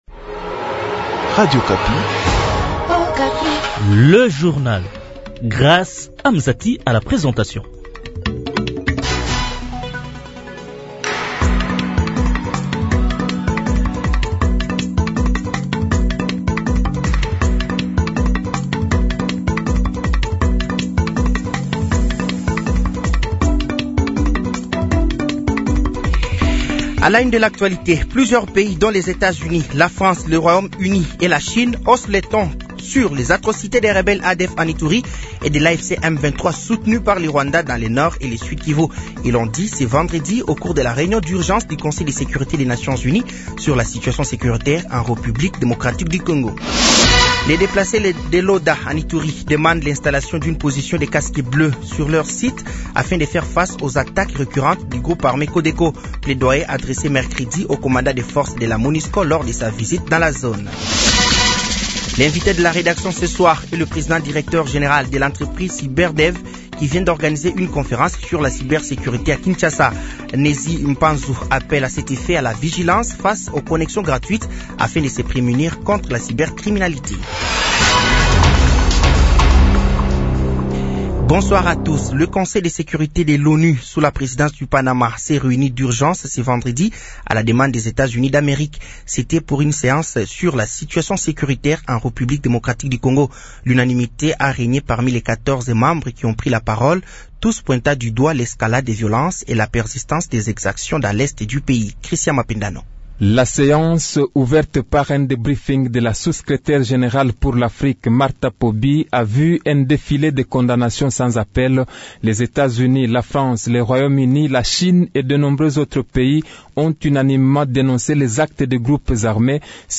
Journal français de 18h de ce vendredi 22 août 2025